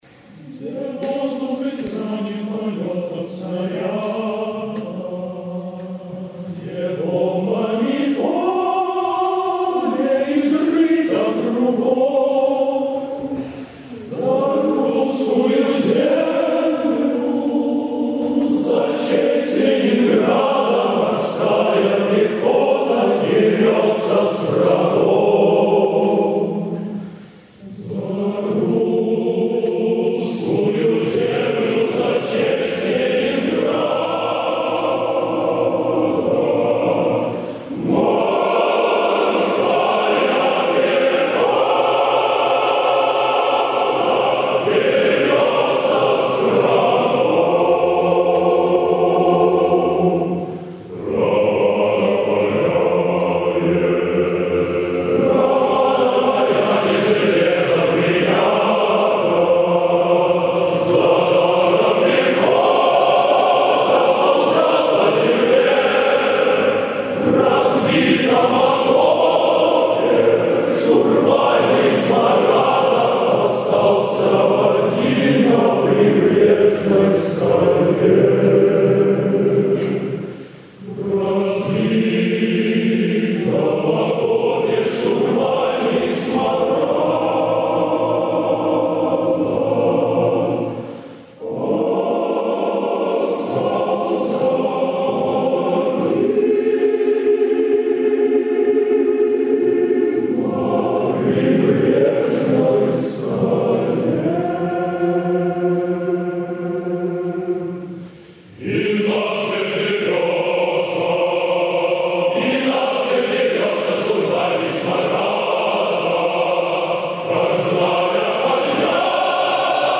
Запись из БКЗ.